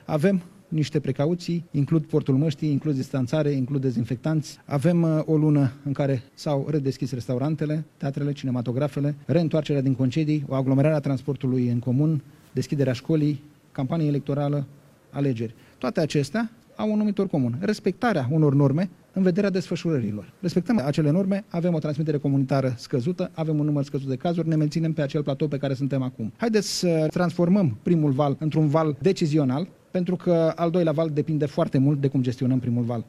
Trei milioane de doze de vaccin antigripal au fost comandate de România, a anunţat, astăzi, la Iaşi, ministrul Sănătăţii, Nelu Tătaru.